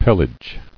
[pel·age]